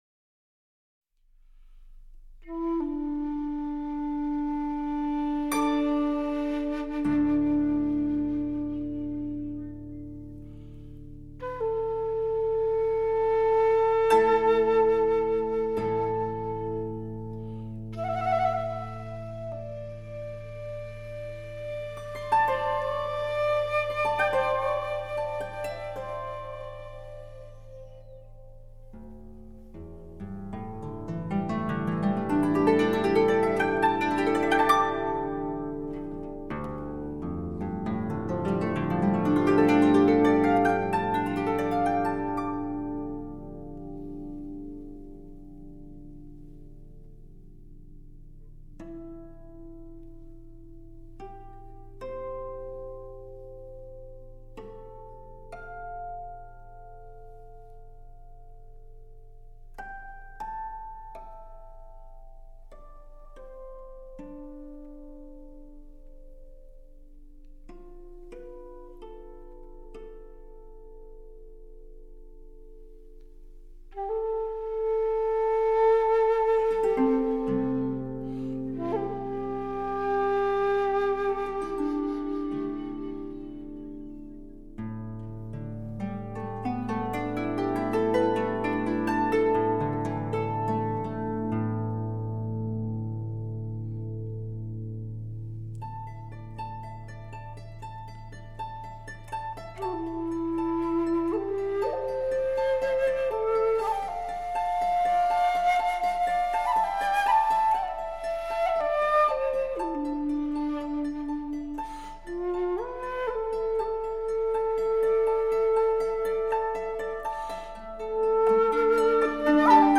箜篌拨弄涟漪 叹息轻落得时光
胡琴动情怀袖 感悟历史的荒凉与深邃
中央电视台480平方米录音棚